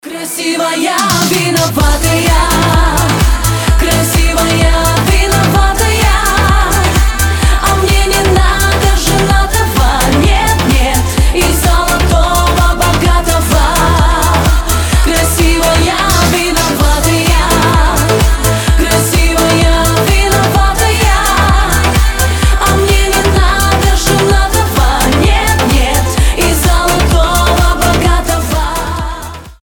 • Качество: 320, Stereo
поп
громкие
женский вокал